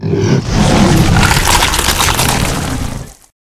dragonbite.ogg